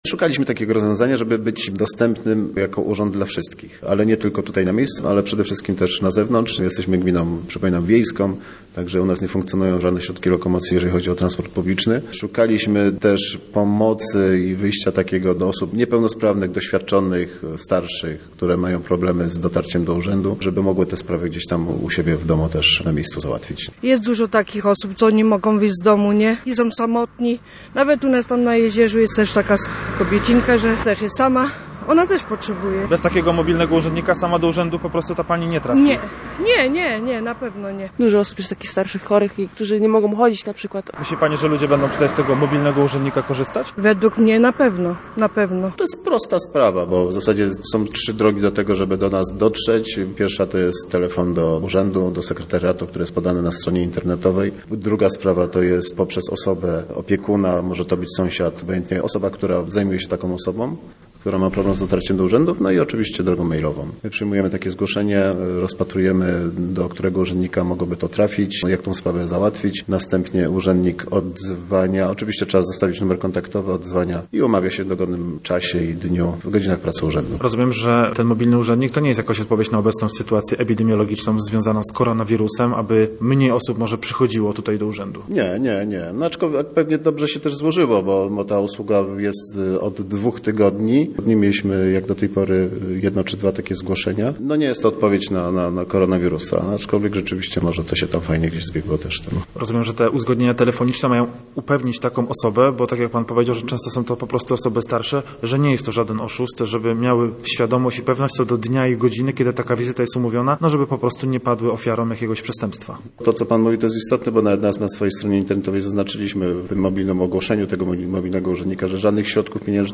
– Chcemy być jako urząd dostępni dla wszystkich, nie tylko tutaj, na miejscu, ale też na zewnątrz. Jesteśmy gminą wiejską, nie mamy transportu publicznego. Wiele osób jest chorych, niepełnosprawnych i starszych. Teraz będą one mogły zamówić taką wizytę u siebie w domu – mówi Radiu Gdańsk Artur Kalinowski, wójt gminy Kołczygłowy.